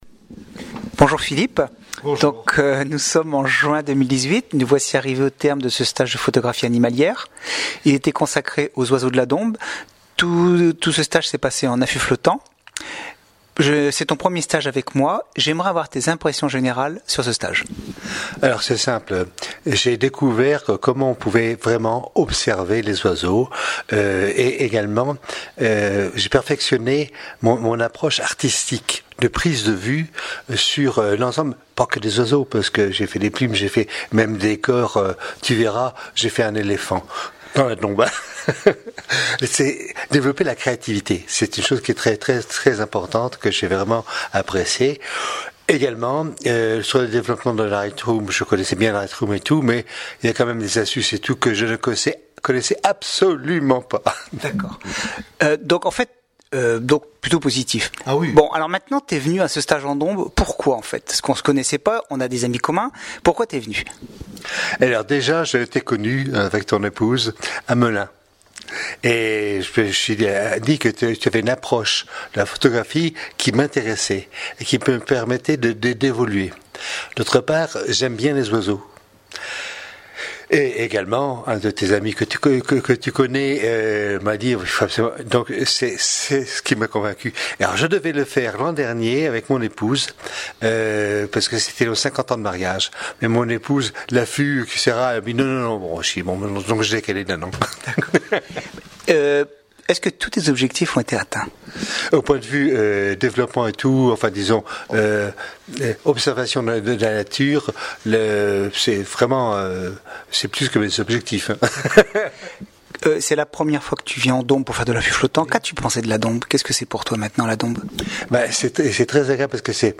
Témoignages écrits et oraux des participants